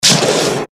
Gemafreie Sounds: Industrie